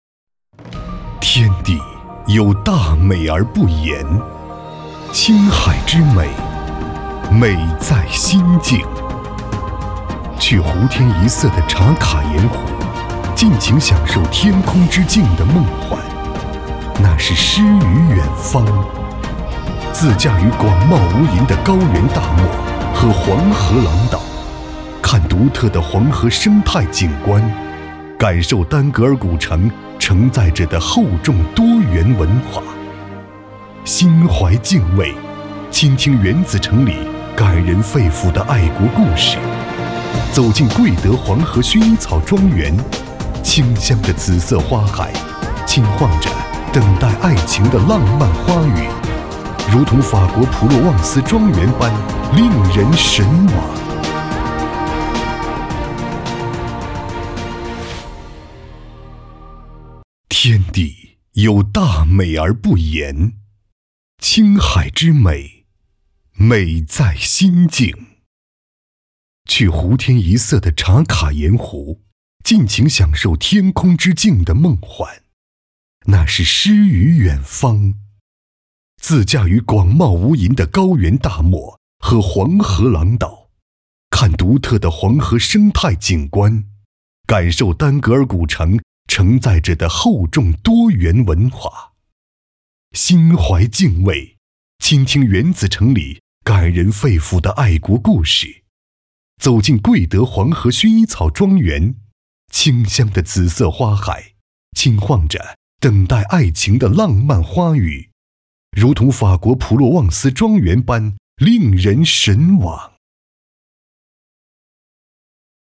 男55号配音师 点击进入配音演员介绍 进入后下滑播放作品集↓↓↓ 配音演员自我介绍 S级配音师，原市电视台新闻播音员、主持人播音与主持艺术专业本科 从业八年，有播音员主持人资格证、记者证。央视配音员，声音浑厚、大气、庄重，擅长党政军警企专题片传片、纪录片等。
宣传片